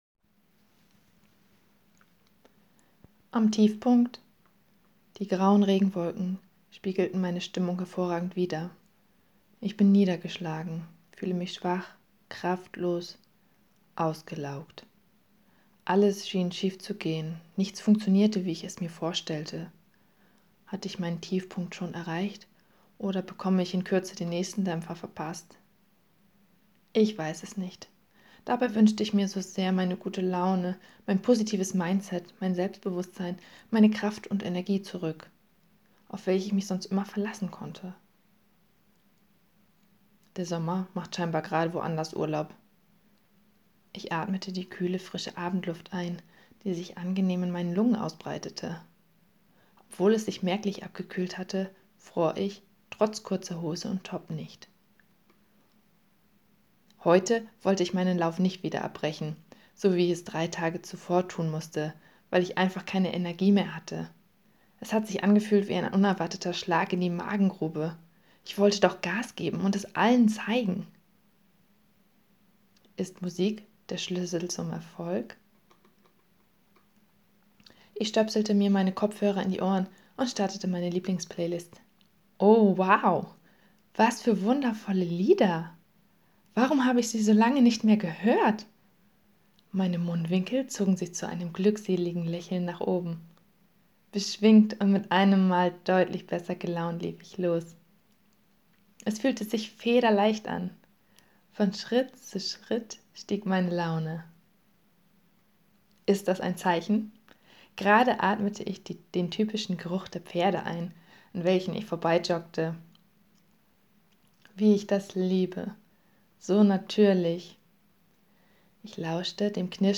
Gerne lese ich dir alles vor: